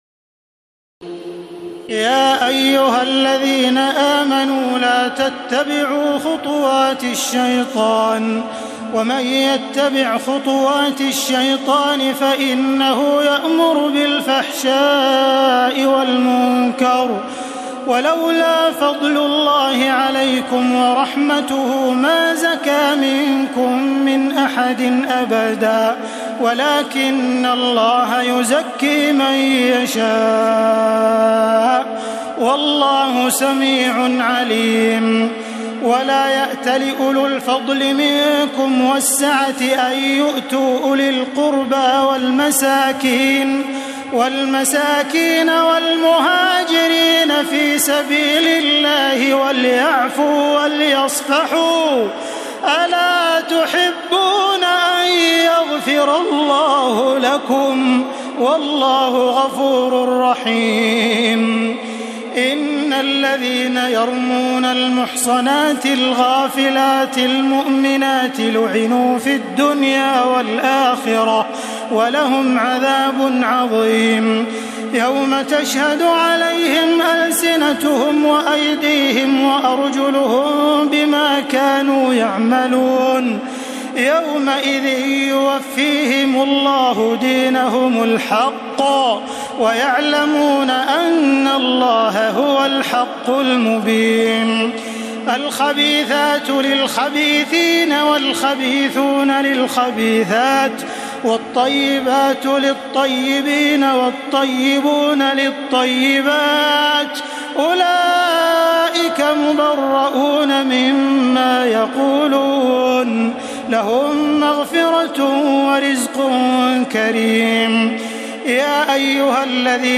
تراويح الليلة السابعة عشر رمضان 1432هـ من سورتي النور (21-64) و الفرقان (1-20) Taraweeh 17 st night Ramadan 1432H from Surah An-Noor and Al-Furqaan > تراويح الحرم المكي عام 1432 🕋 > التراويح - تلاوات الحرمين